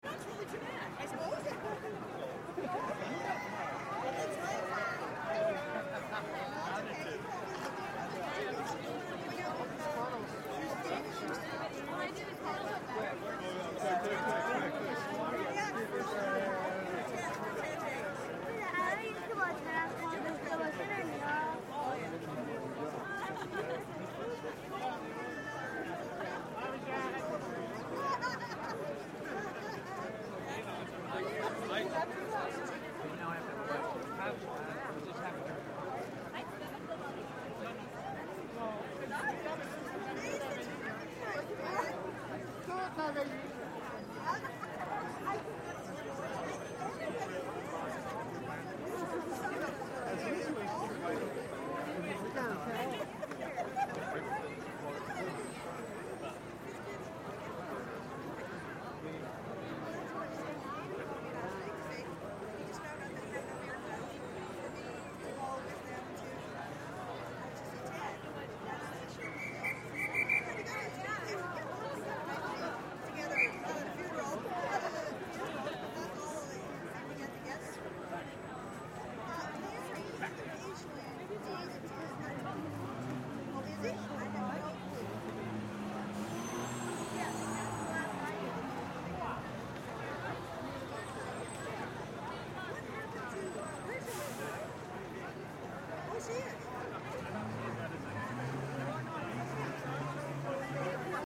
8. Уличные беседы на английском